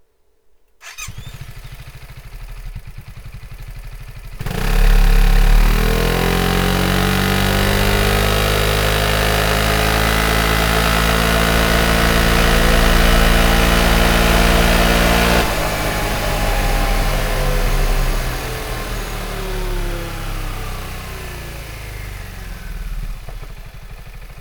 Mit einem tief klingenden und einzigartigen Akrapovič Sound zur Unterstreichung des sportlichen Charakters der Scooter.
Sound Akrapovic Slip-On